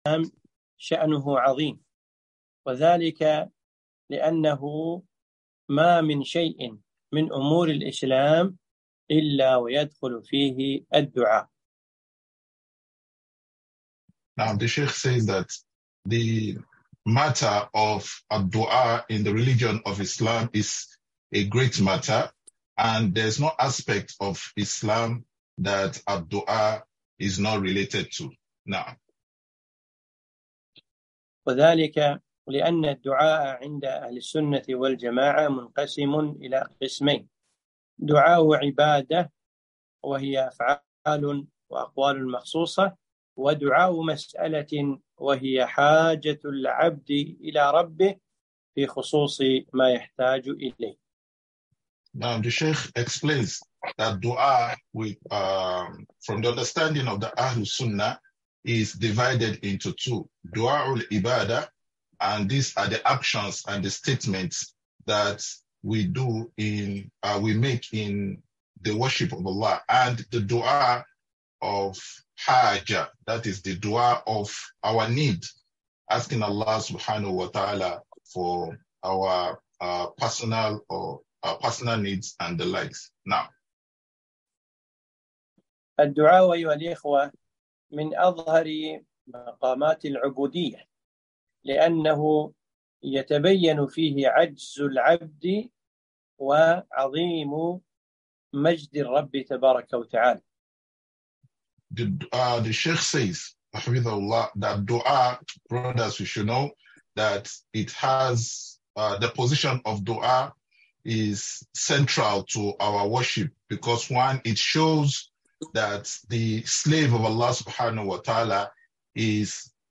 محاضرة - الدعاء جوهر العبادة (مترجمة إلى الانجليزي)